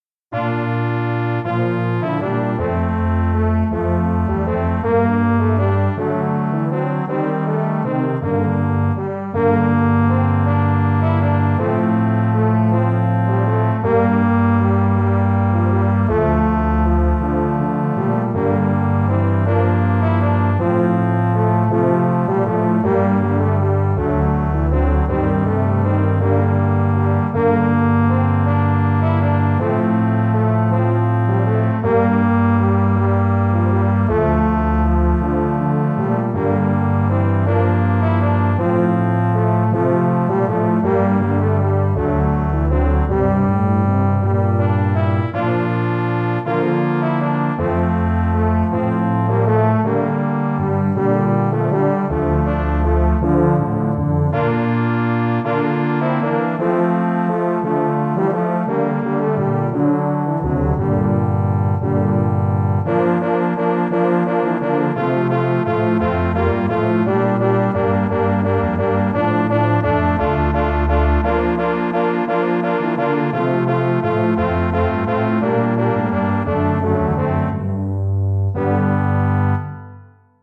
Traditional
Bearbeitung für Hornquartett
Besetzung: 4 Hörner
Arrangement for horn quartet
Instrumentation: 4 horns